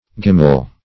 Gimmal \Gim"mal\, a.